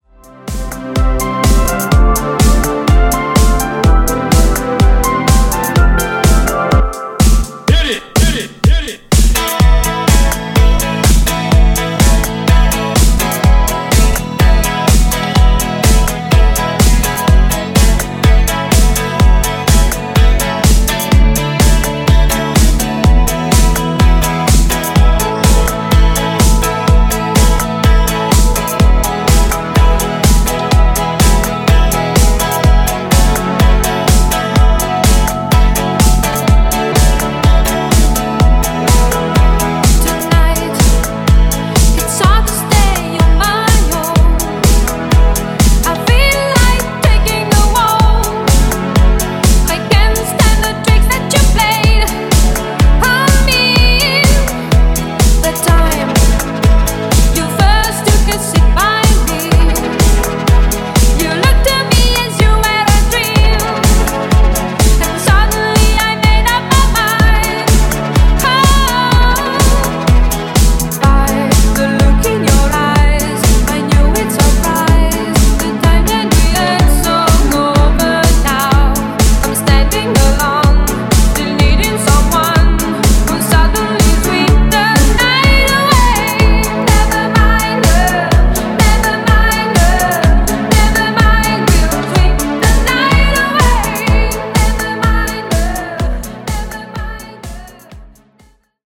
Club Extended)Date Added